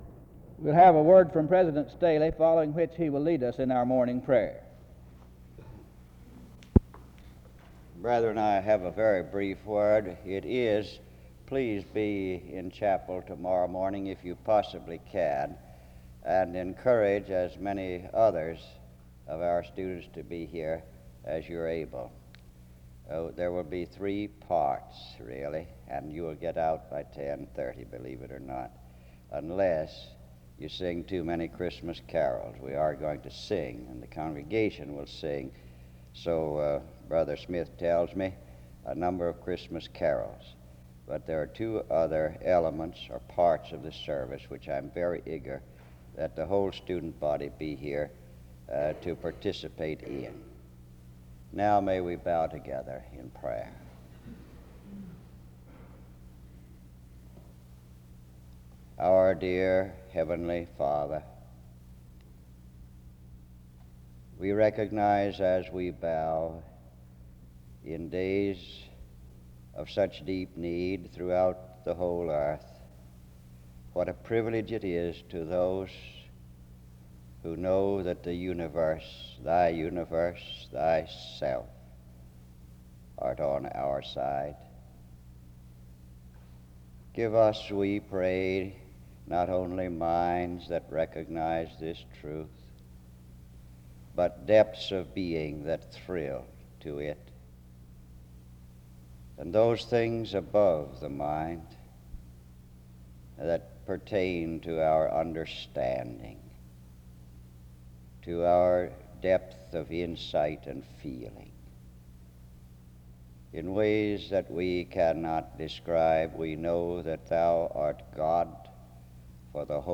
In Collection: SEBTS Chapel and Special Event Recordings SEBTS Chapel and Special Event Recordings